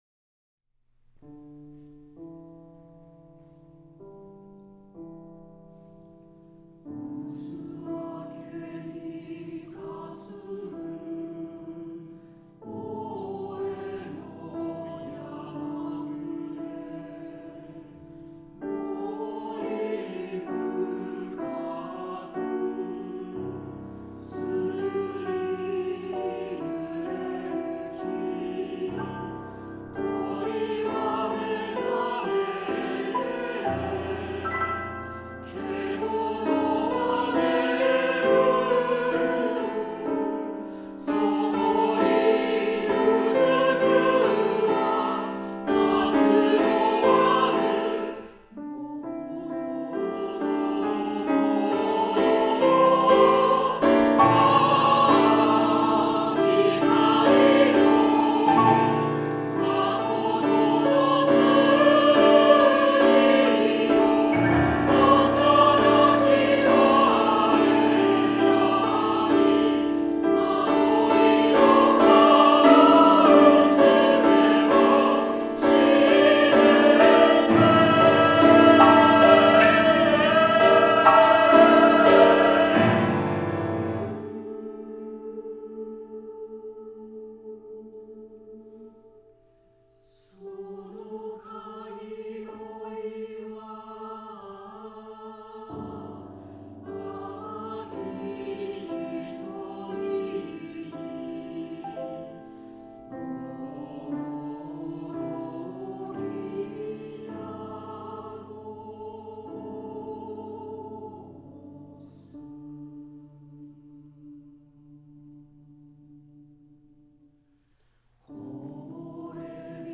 聖歌や声明を想わせる荘重さが本分。
[ 斉唱 ]